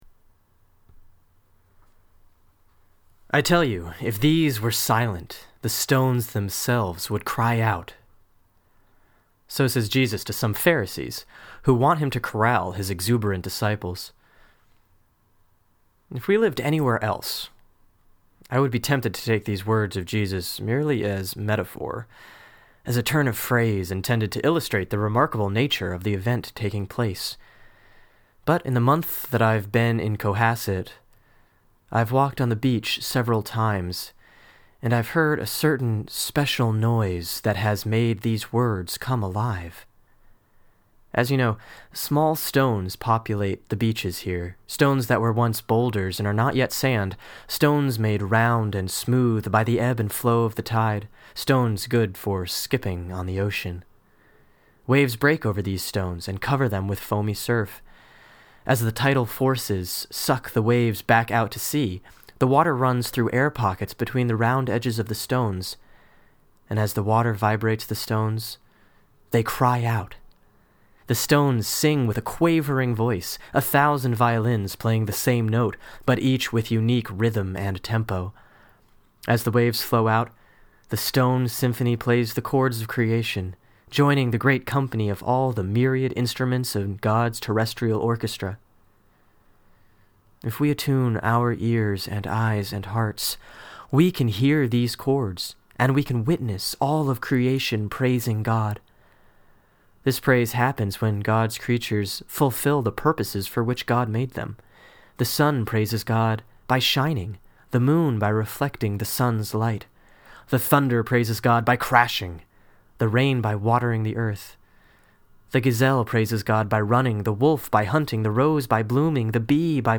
(Sermon for March 28, 2010 || Palm Sunday, Year C, RCL || Luke 19:28-40)